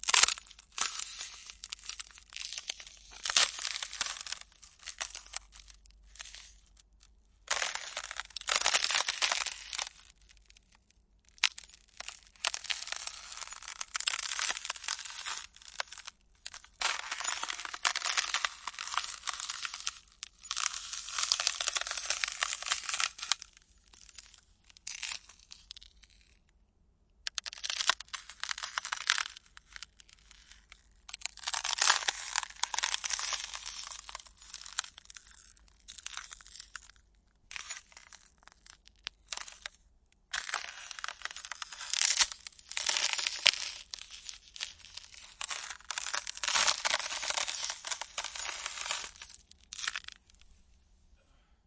我用一对soundman okm2话筒作为接触话筒，固定在冰冻的湖面上，然后记录下在冰面上投掷或滑过几个石头和卵石发出的声音。
标签： 寒意 冬天 裂纹 现场记录 湖泊 防滑
声道立体声